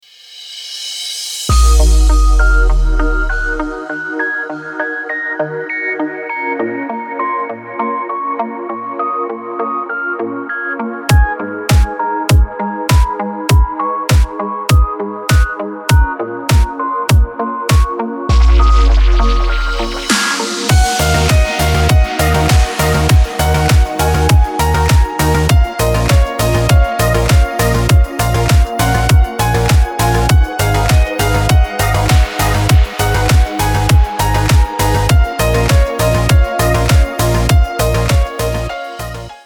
• Качество: 256, Stereo
красивые
без слов
красивая мелодия